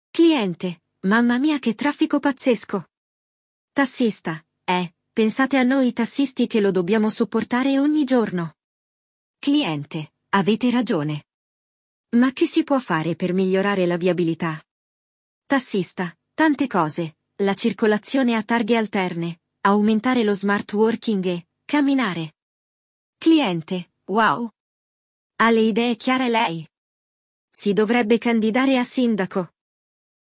Neste episódio do Walk ‘n’ Talk Level Up, vamos acompanhar uma conversa entre um taxista e seu cliente sobre mobilidade urbana, o trânsito e como resolver os problemas relacionados ao tema.
Você pode repetir as frases junto com o áudio, imitando a entonação e o ritmo dos falantes nativos.